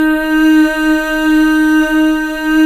Index of /90_sSampleCDs/Club-50 - Foundations Roland/VOX_xFemale Ooz/VOX_xFm Ooz 2 S